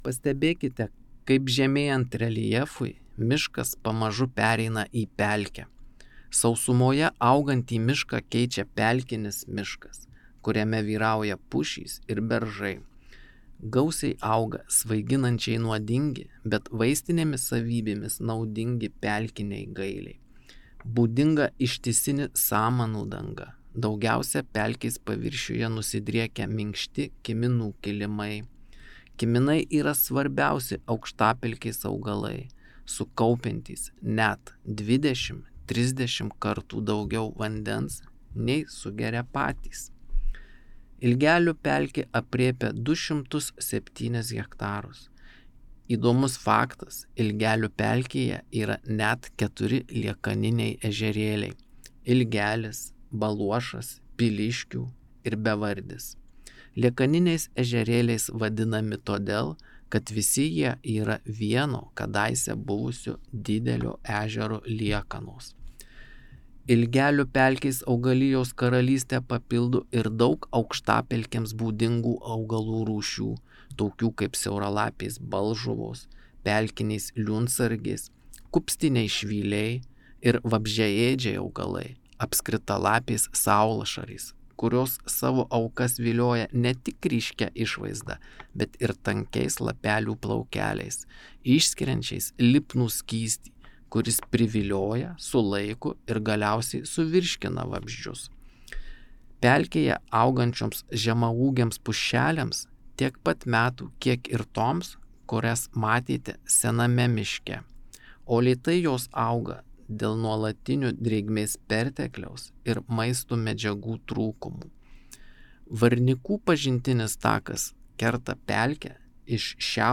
Jūs klausote miškininko pasakojimo